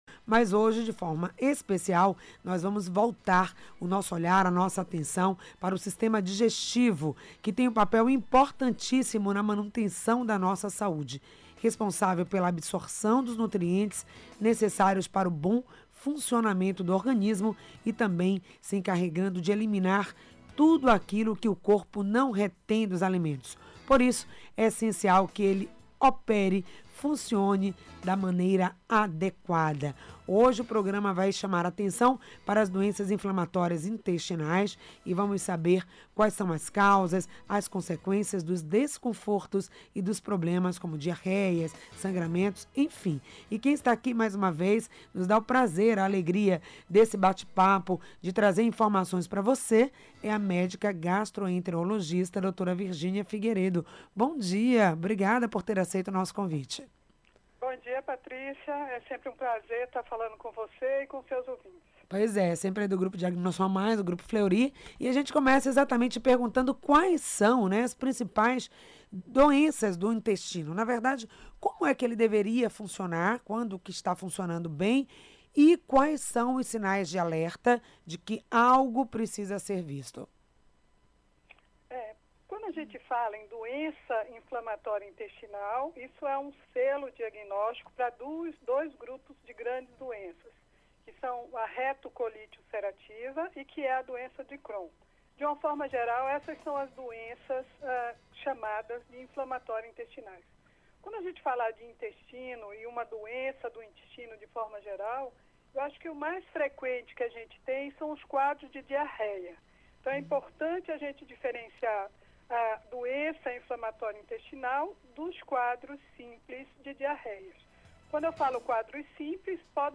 por telefone